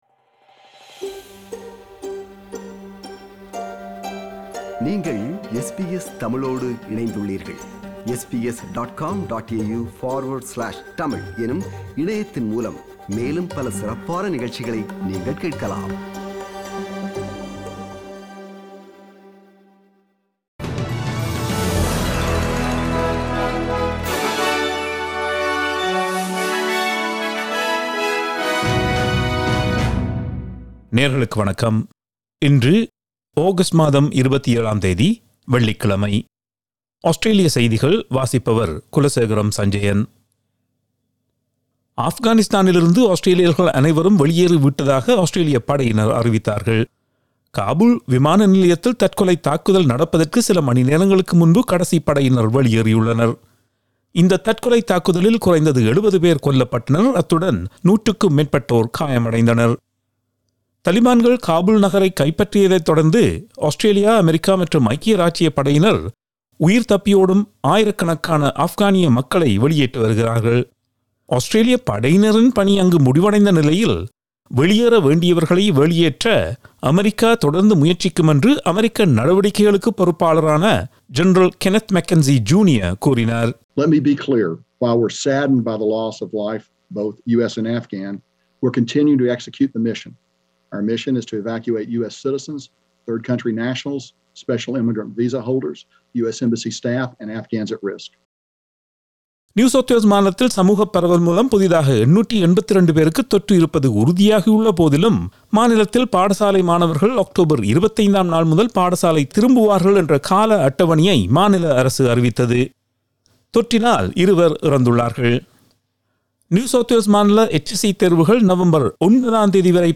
Australian news bulletin for Friday 27 August 2021.